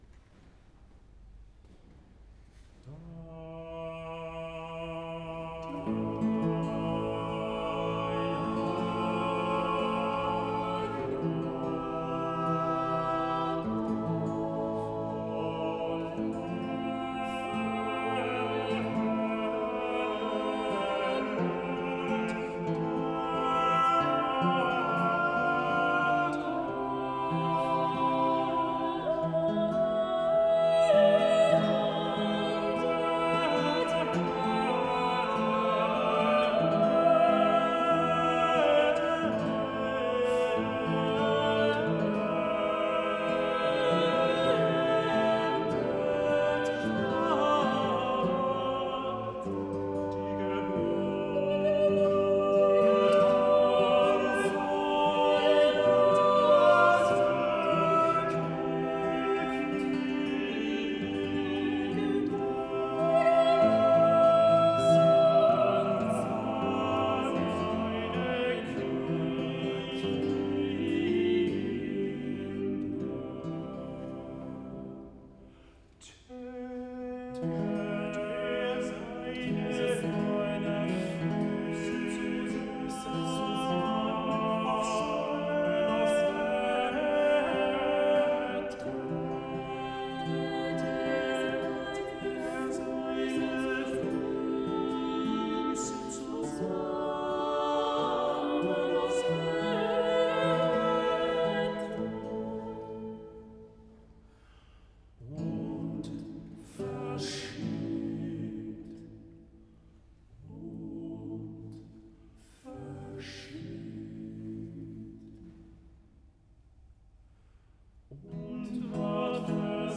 Avec l’autorisation de RTS-Espace 2, vous pouvez ici réécouter le concert des Fontaines d’Israël de Schein donné à la collégiale de St-Ursanne en 2018.